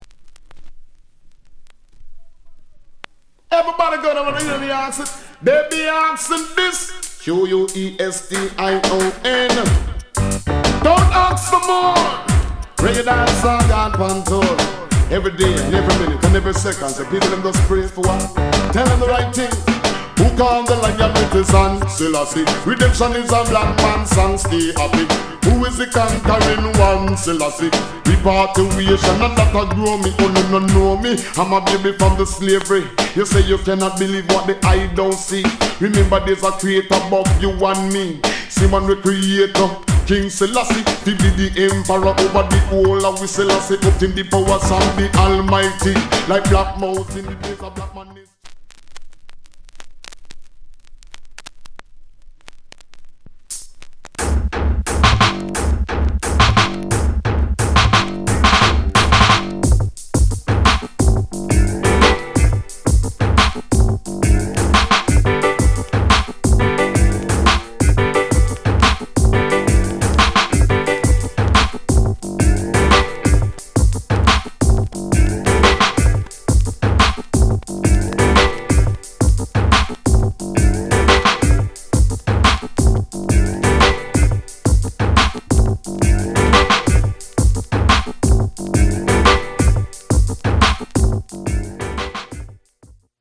* '95 Jump Up trk